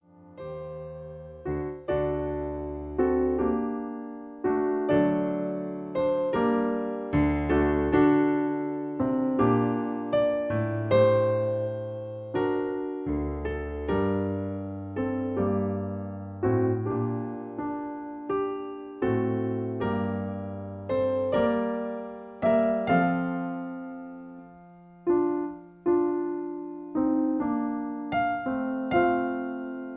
In G. Piano Accompaniment